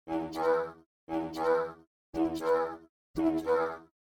巡音ルカとcubaseでボス前の警告音のような感じで「Danger」音を作ってみました↓
これをcubaseで読み込んで、オクターバーとボイスチェンジャーで加工して
フランジャーとディレイを掛けて作成しました。
ボコーダーぽいですね。